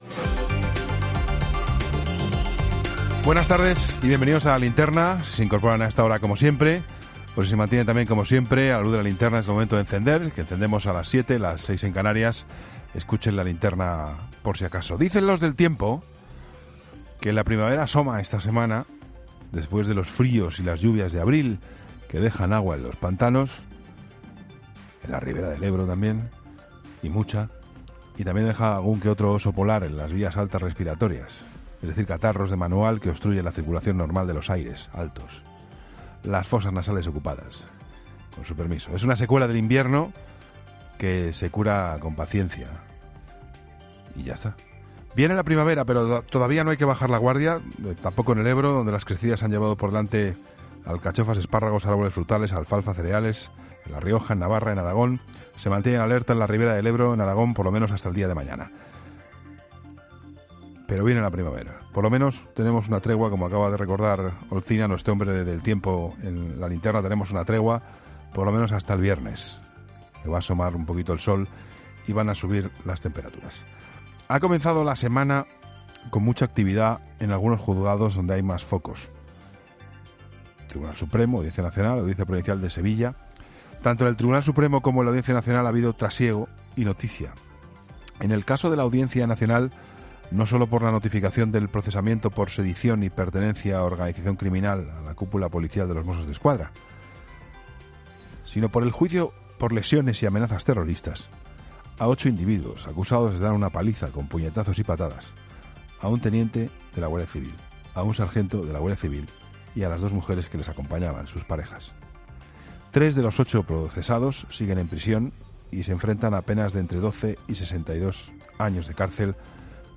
El director de ‘La Linterna’ Juan Pablo Colmenarejo ha centrado su comentario en los diversos procesos judiciales con los que comenzamos la semana: la agresión a los guardias civiles en Alsasua y el caso de los EREs falsos de la Junta de Andalucía